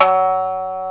Index of /90_sSampleCDs/AKAI S-Series CD-ROM Sound Library VOL-1/3056SHAMISEN